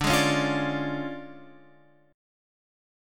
D Minor Major 7th Flat 5th